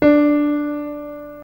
C_DO.ogg